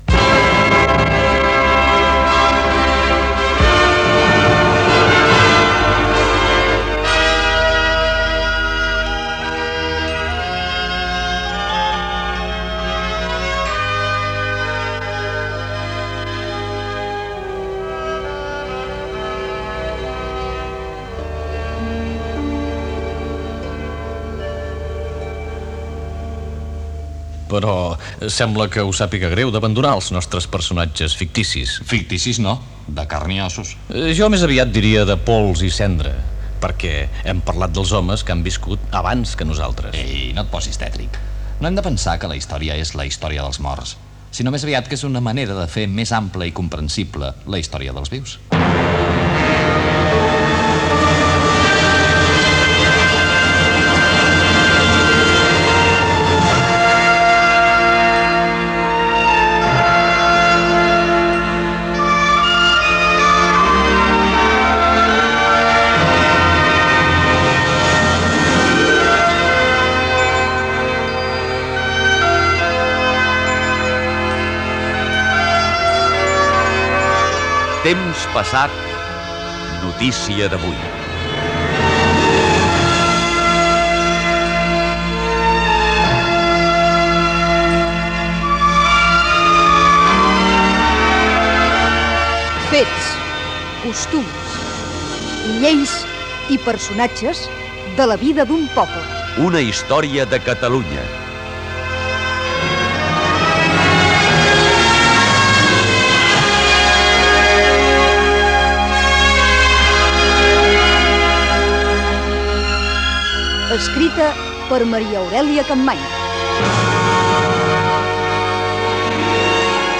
Últim capítol de la sèrie, el número 46, "Epíleg" Gènere radiofònic Cultura